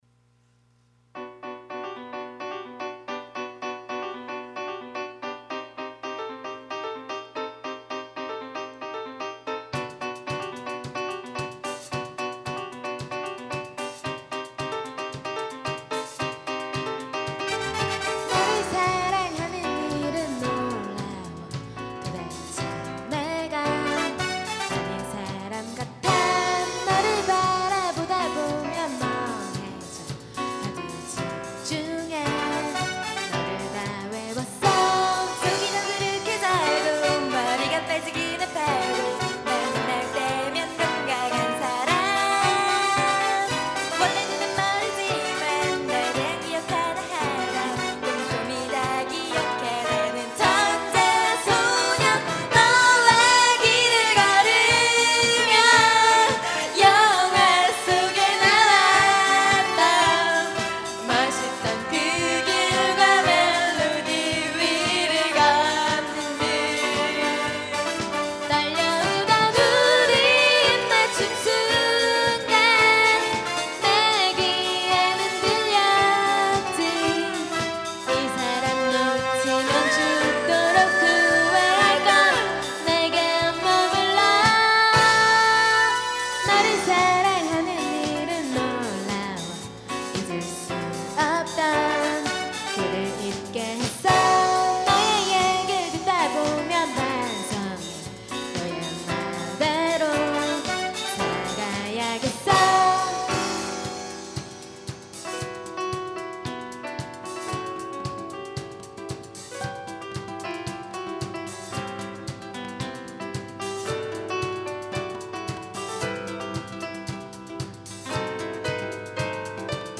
2005년 신입생 환영공연
홍익대학교 신축강당
어쿠스틱기타
드럼
신디사이저